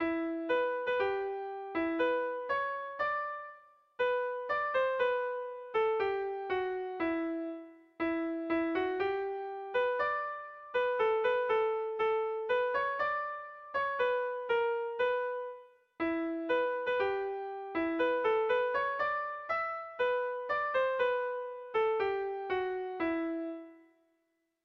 Bertso melodies - View details   To know more about this section
ABDAB